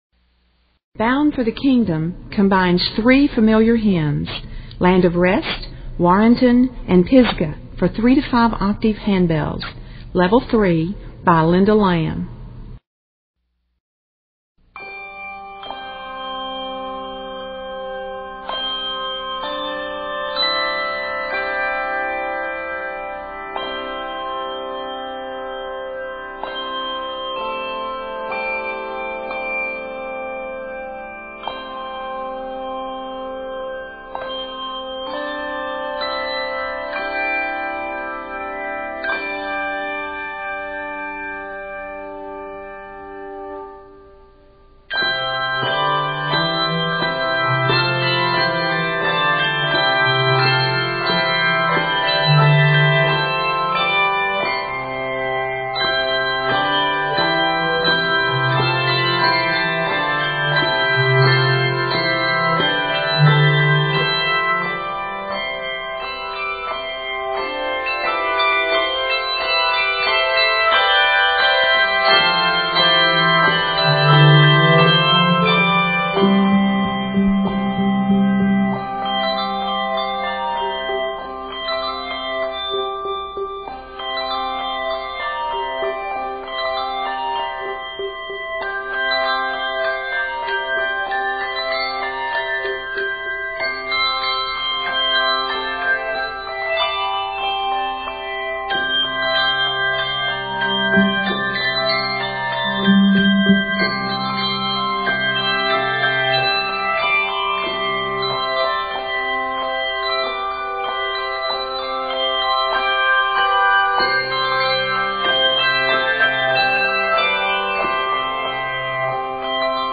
A lively folk-like motive joins the melodies together.